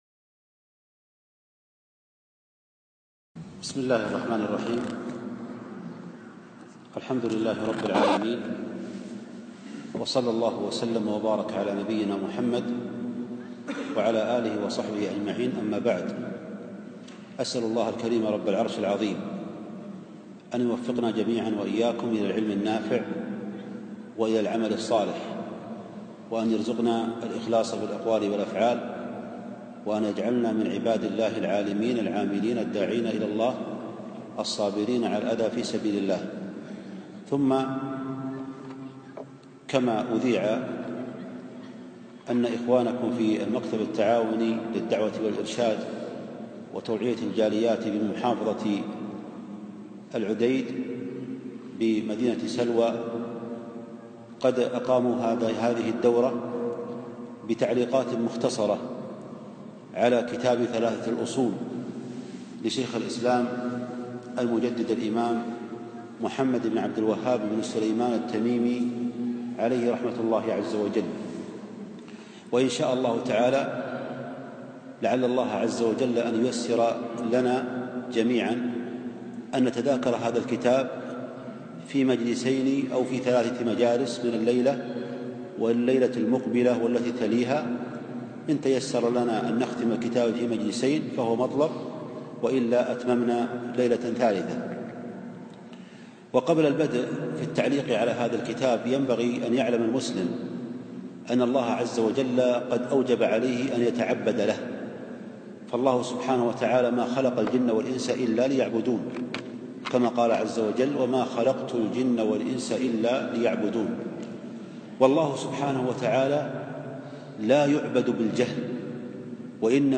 أقيم الدرس بمحافظة العديد بسلوى في جامع عمر بن الخطاب